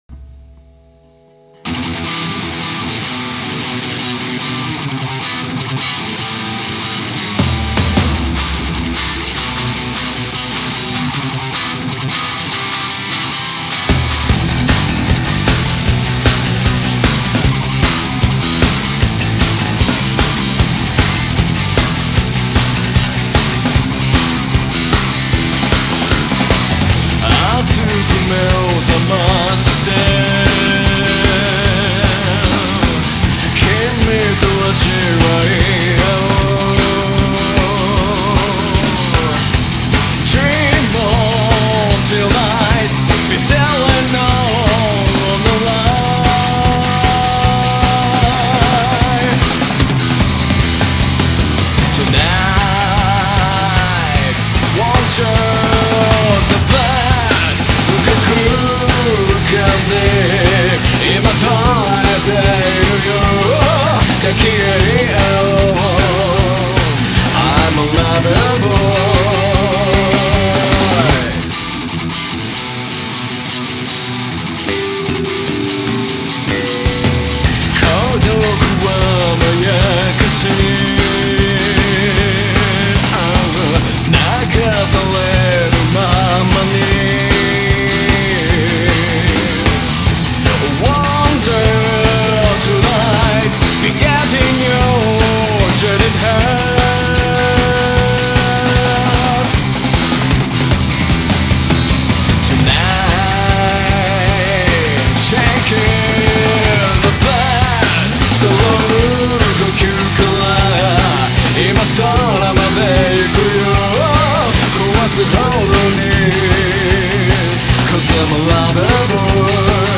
jROCK